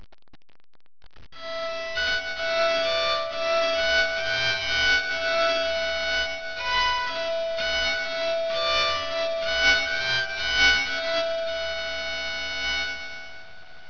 BOWED PSALTERY
Bowed Psaltery Sound Clips
There is no fretting: each string is simply tuned to a different note (the scale of C on one side with the "accidentals" - the sharps and the flats - on the other). It gives a delightfully high, echoing, ethereal sound - or, if you don't like it, it sets your teeth on edge!